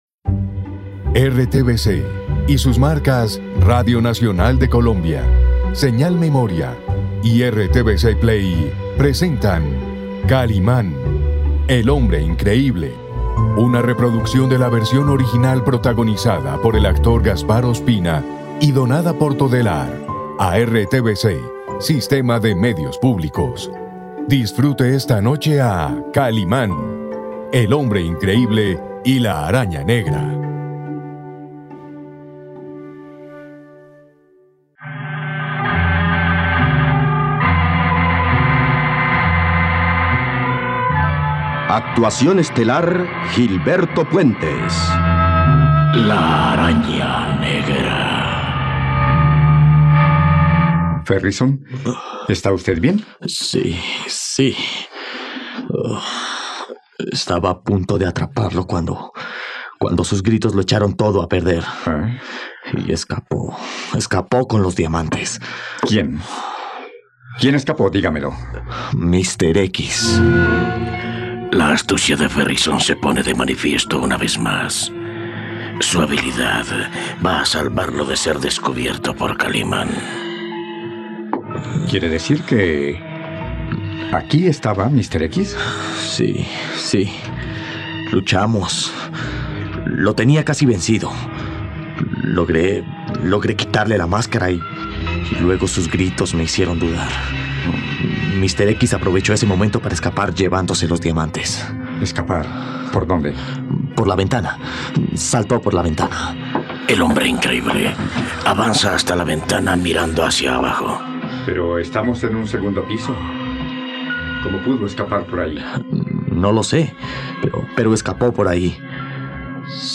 Lord Ferrison en el suelo culpa a Kalimán por la huida de Mr. X, mientras que el hombre increíble interroga a Ferrison por encontrarse en ese lugar. Asset ID 0 Arriba 0% Down 0% Producción Kalimán, el hombre increíble: La Araña Negra Tags refugio radionovela Diamante robo Kalimán Superhéroe Duración 17Minutos Archivo Kaliman Araña Negra Cap 94 Con promos.mp3 (16.47 MB) Número de capítulo 94